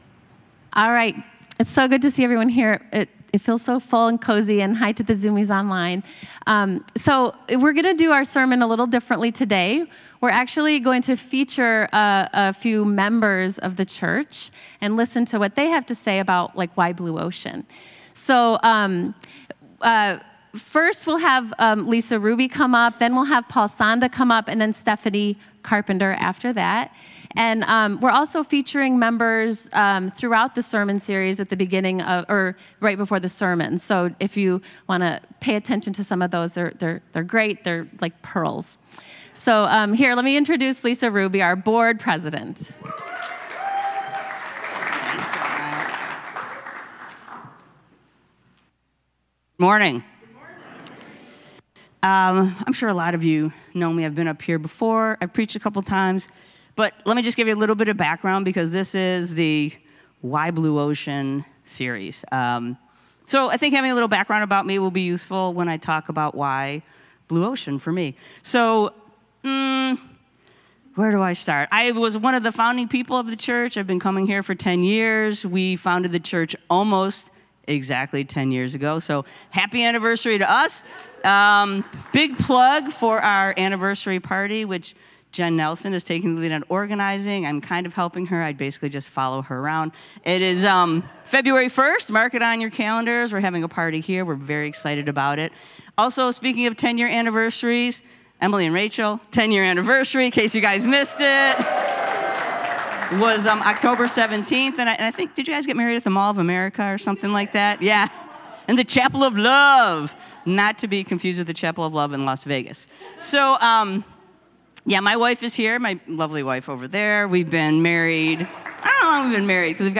Three different congregants share about their personal faith journey and why they’ve made Blue Ocean their church home.